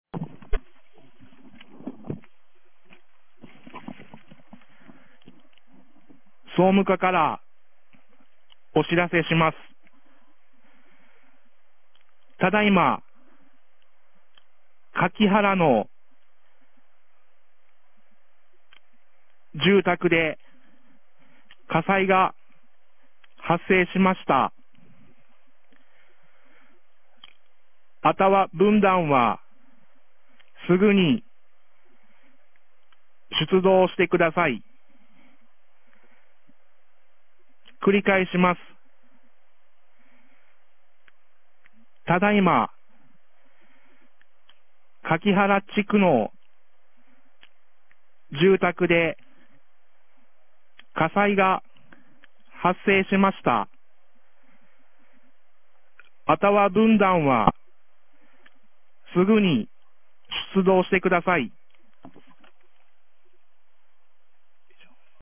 ■防災行政無線情報■ | 三重県御浜町メール配信サービス
放送音声